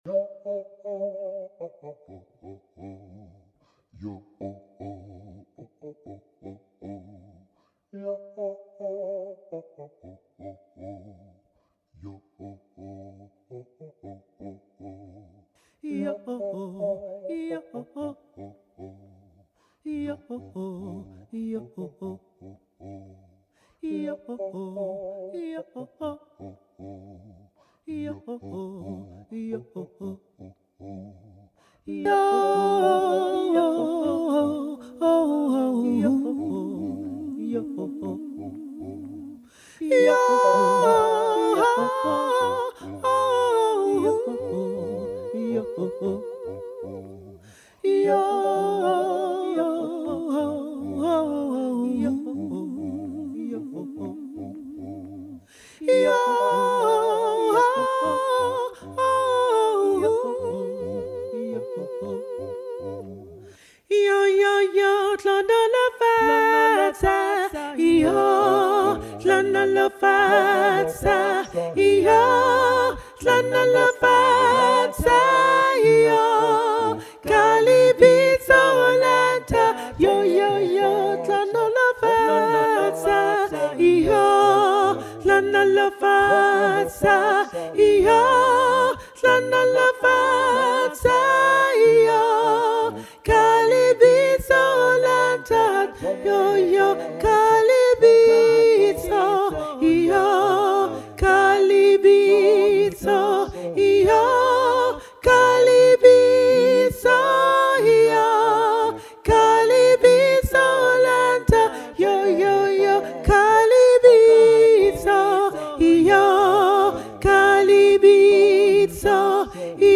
Hlonolofatsa-Sop.mp3